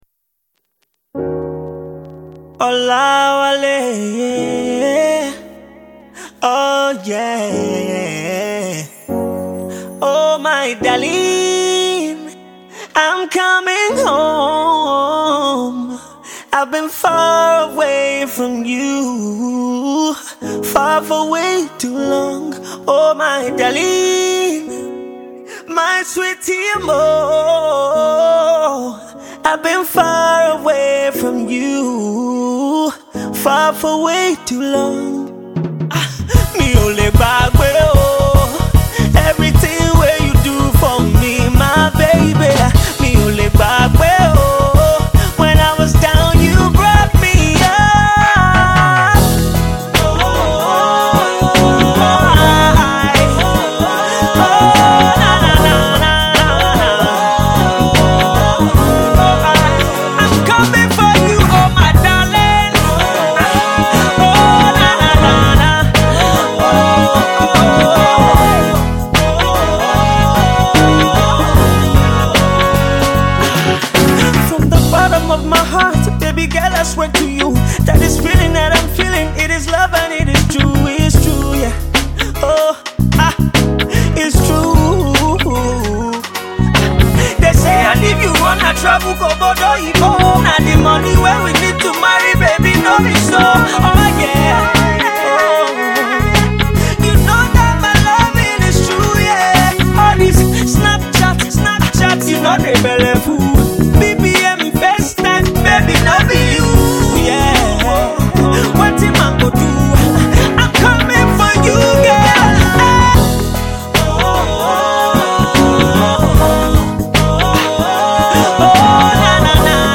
lovely & catchy tune